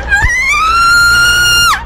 Worms speechbanks
uh-oh.wav